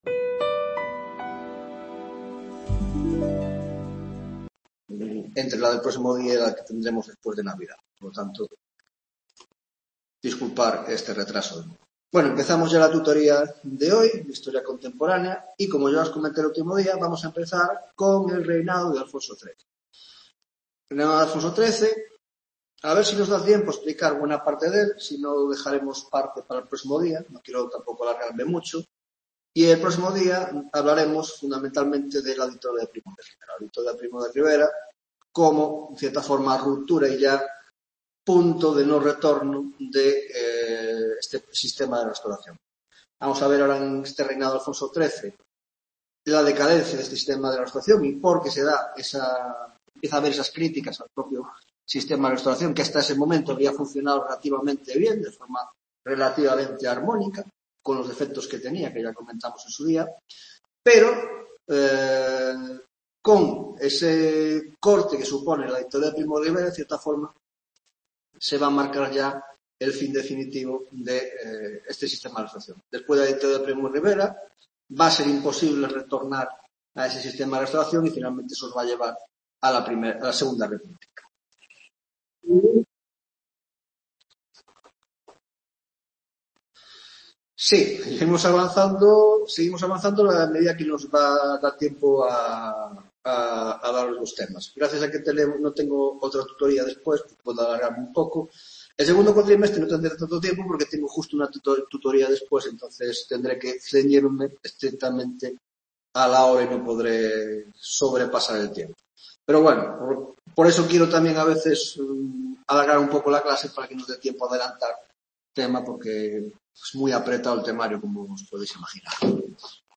10ª tutoría de Historia Contemporánea